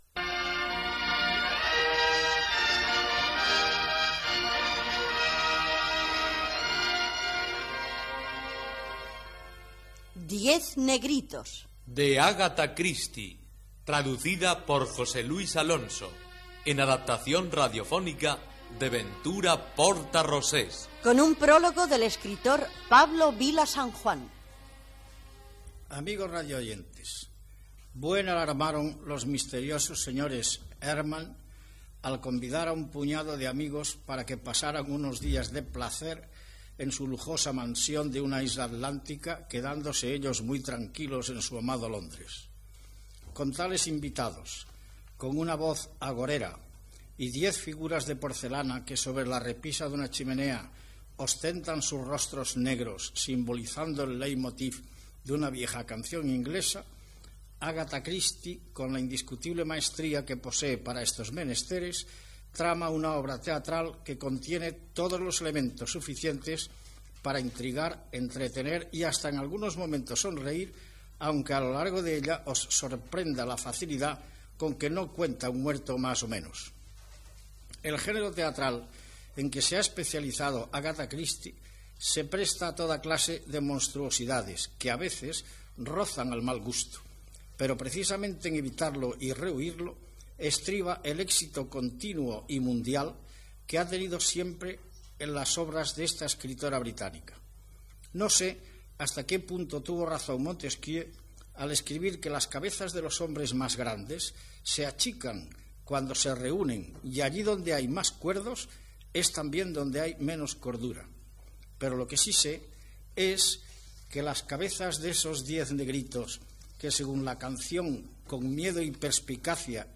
Gènere radiofònic Ficció